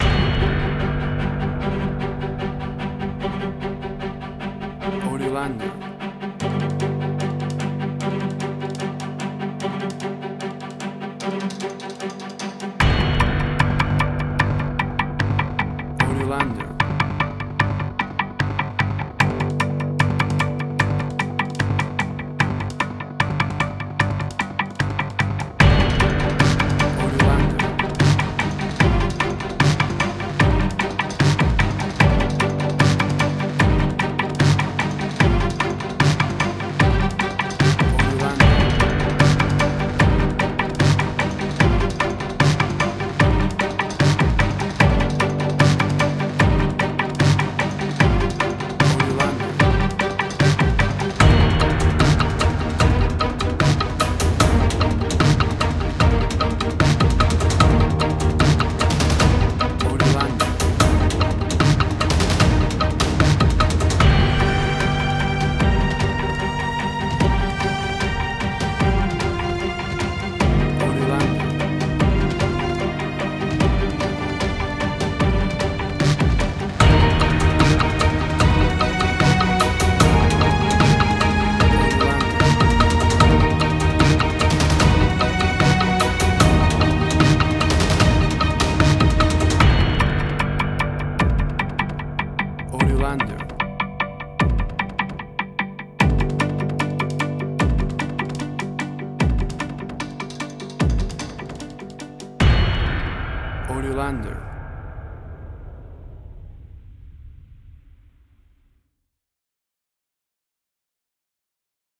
Suspense, Drama, Quirky, Emotional.
Tempo (BPM): 150